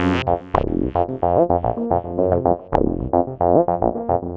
UR 303 acid bass 1 e.wav